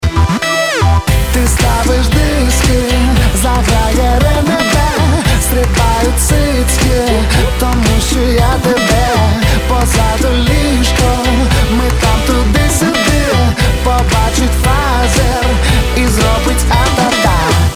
• Качество: 192, Stereo
приятные